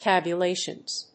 /ˌtæbjʌˈleʃʌnz(米国英語), ˌtæbjʌˈleɪʃʌnz(英国英語)/